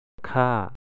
redround.gif (1007 bytes) The Thai sound ph, th, kh, and ch.
ฆ่า to kill khaˆa